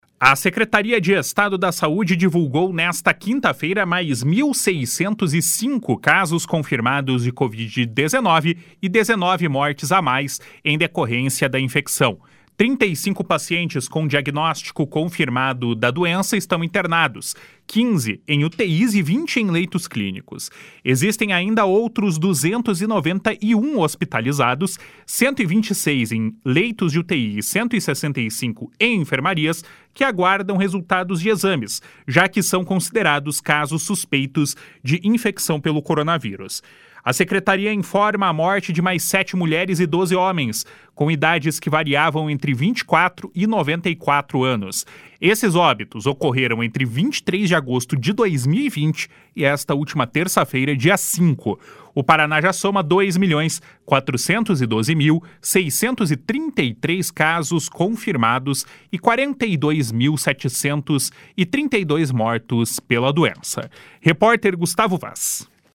BOLETIM DA COVID 19.mp3